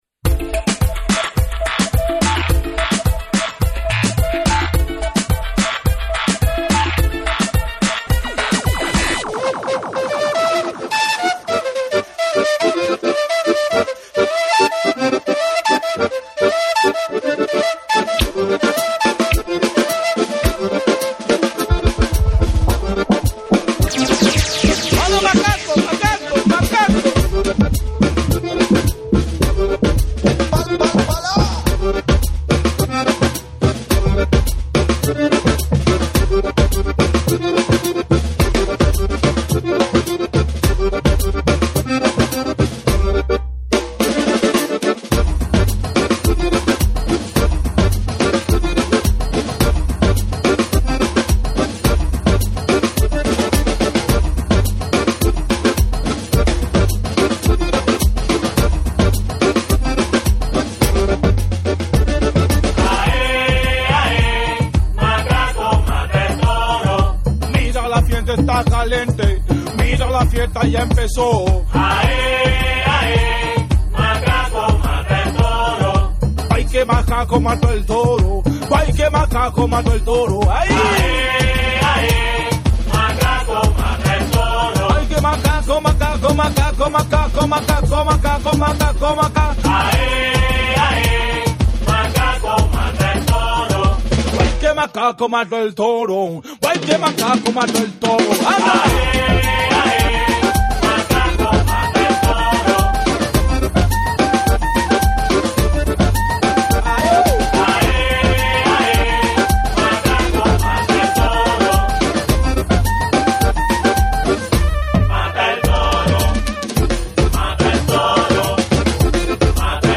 JAPANESE / REGGAE & DUB / NEW RELEASE(新譜)